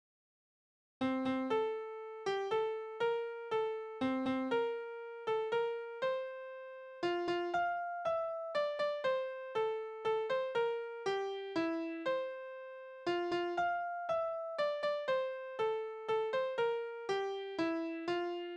Marschlieder: Der begeisterte Wanderer
Tonart: F-Dur
Taktart: 3/4
Tonumfang: Undezime
Besetzung: vokal